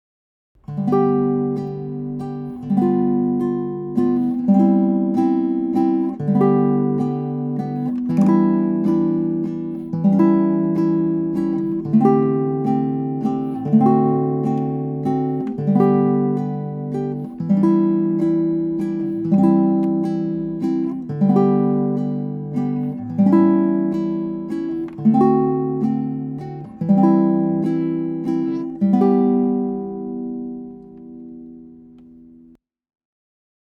If you fancy a more active strum, the flat-three strum we learned for Arirang—three gentle down strums per measure—works well and serves a quasi-metronomic role.
Amazing Grace | Flat-Three Strum